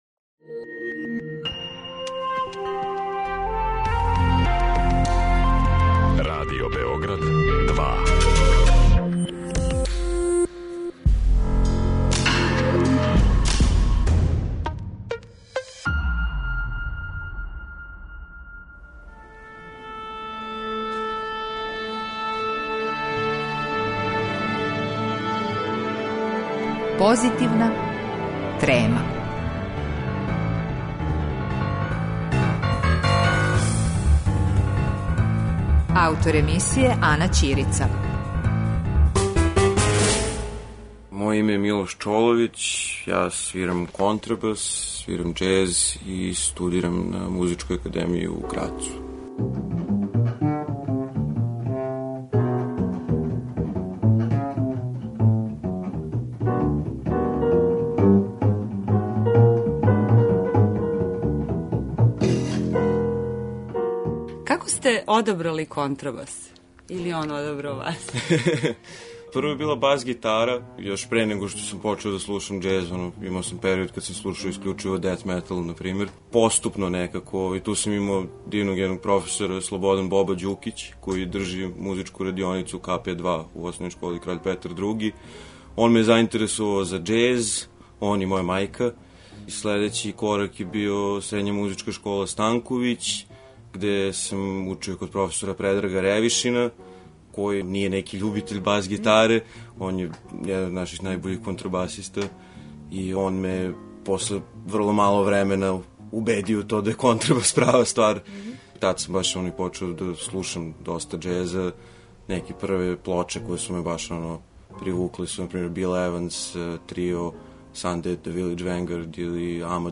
Разговор са контрабасистом